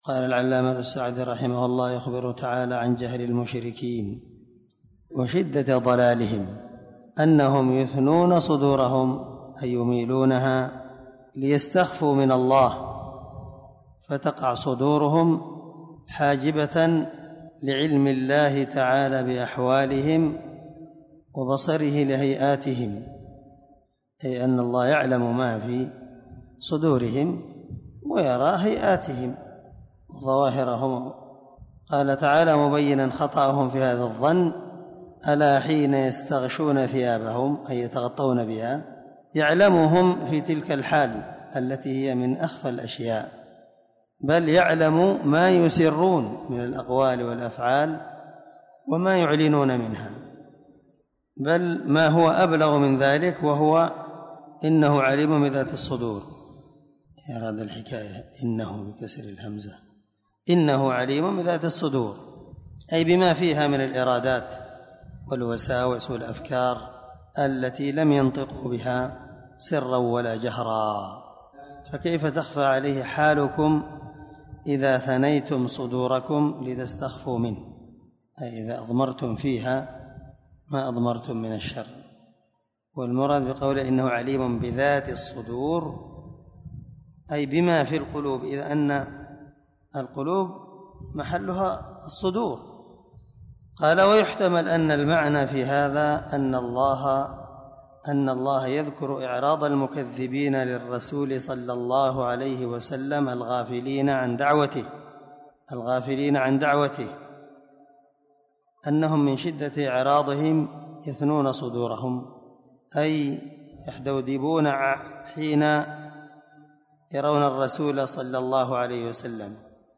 سلسلة_الدروس_العلمية
✒ دار الحديث- المَحاوِلة- الصبيحة.